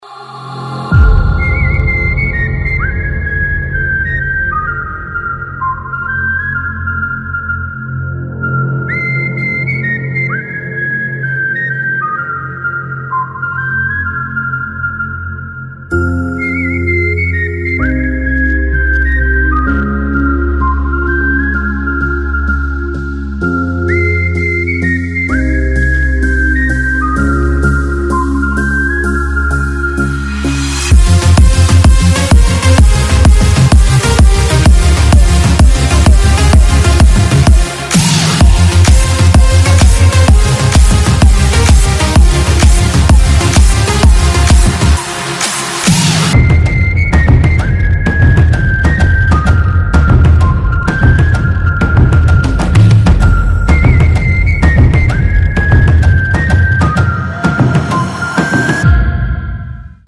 • Качество: 128, Stereo
свист
без слов
progressive house
мелодичный свист